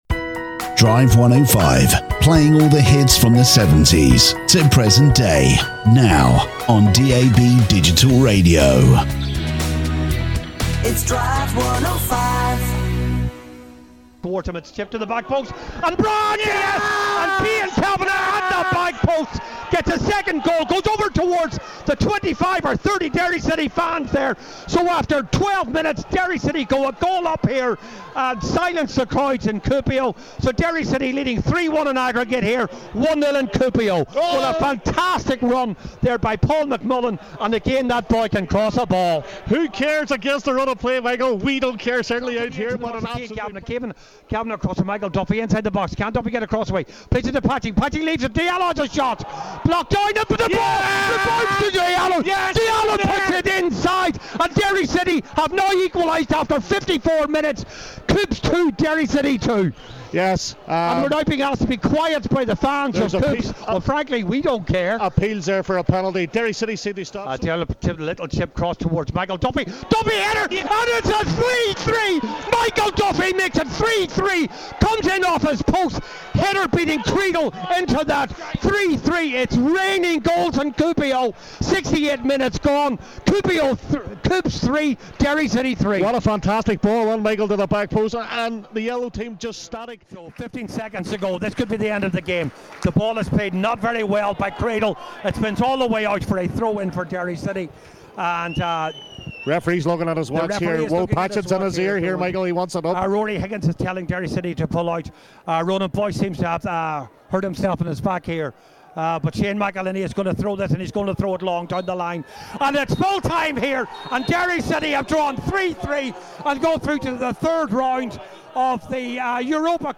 Goal highlights and Interview